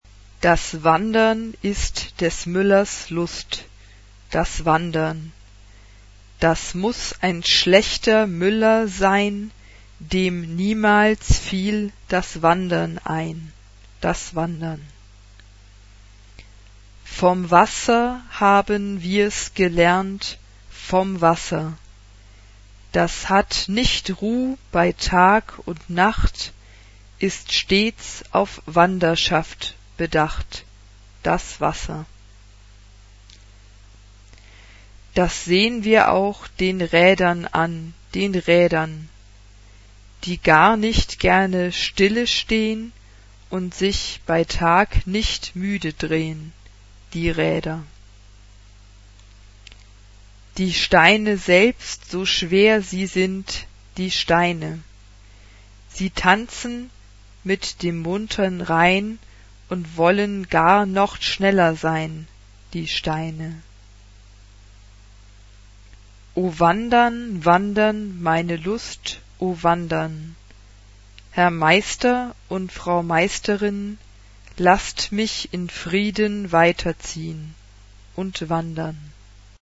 Genre-Style-Form: Secular ; Choir
Piano (1) ; Flute (1) ad libitum
Tonality: F major